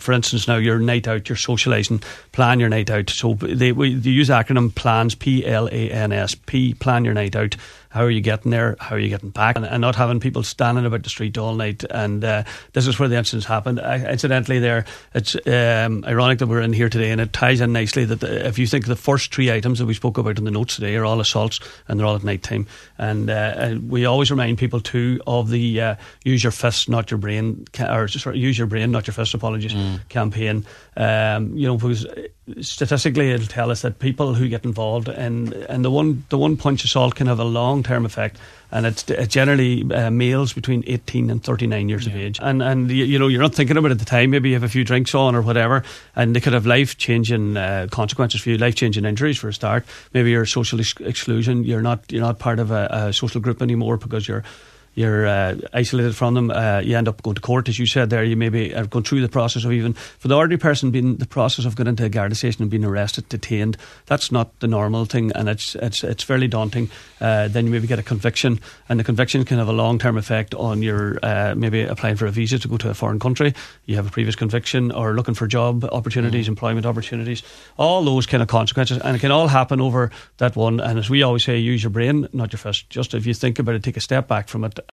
on the Nine ’til Noon Show this morning